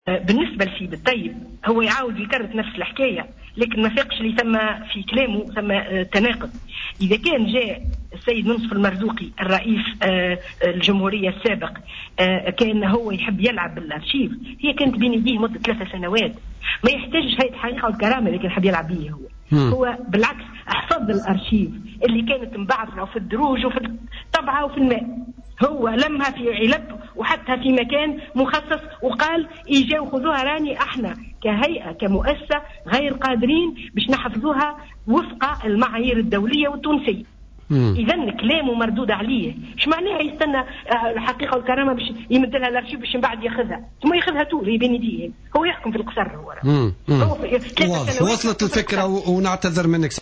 Intervenue sur les ondes de Jawhara Fm, Sihem Ben Sedrine a réagi vendredi aux déclarations du secrétaire général du parti Al Massar, Samir Taieb qui avait jugé que la présidente de l’Instance « dignité et vérité » est tombée dans un piège tendu par Moncef Marzouki.